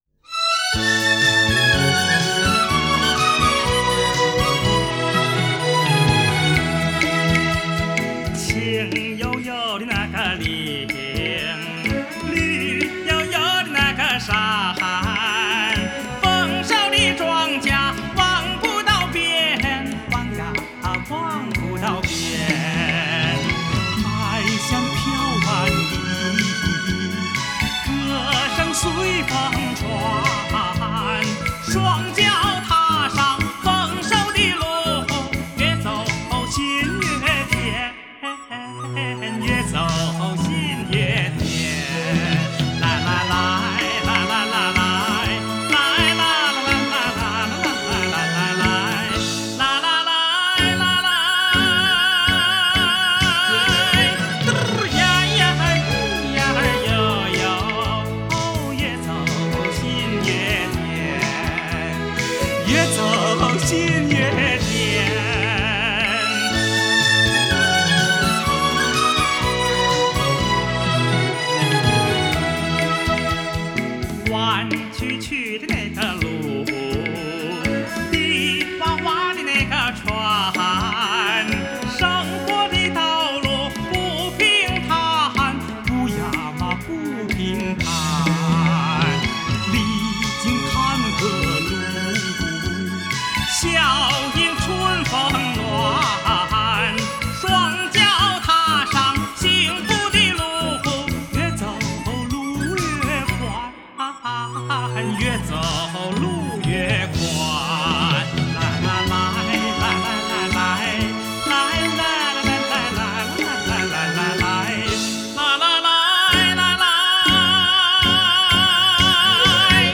主题歌